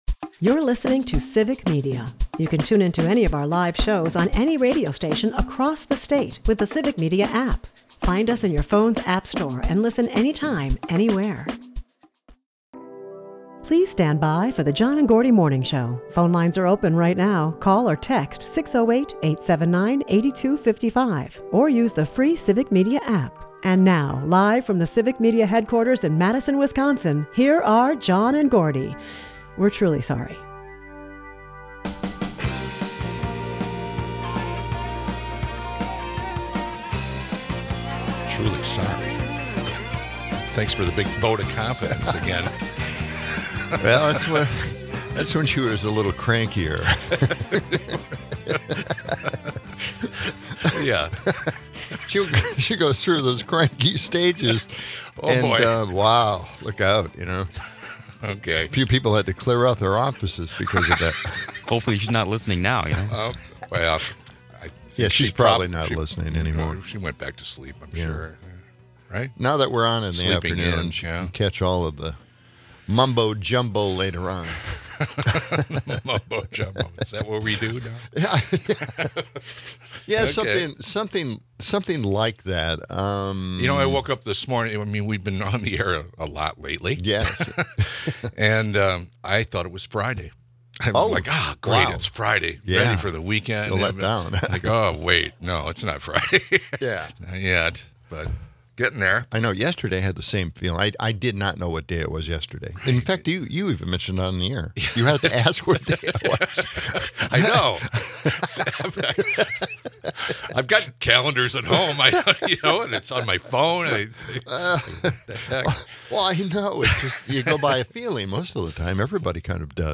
As conspiracy theories swirl around Epstein's financial records and political dramas unfold, the episode teeters between light-hearted banter and serious educational discourse, highlighting the tension between public perception and academic realities.